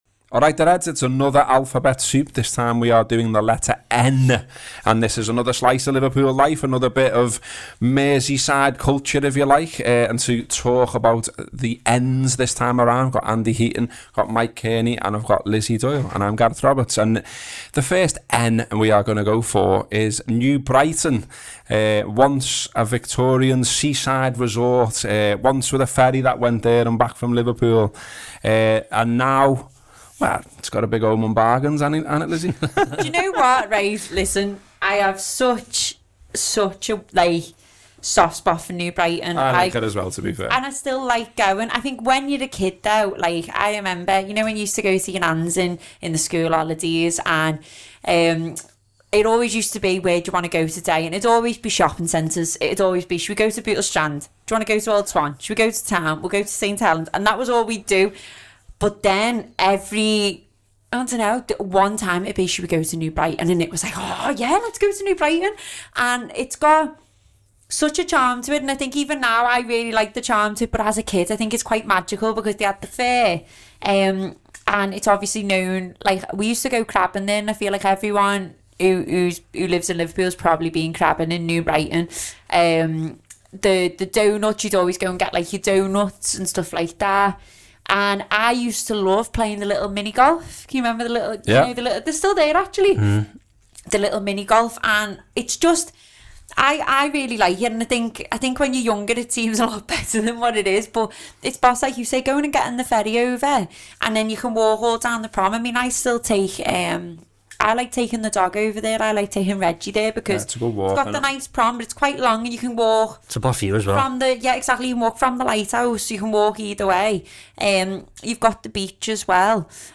Below is a clip from the podcast – subscribe for more on Liverpool; the club, city and culture…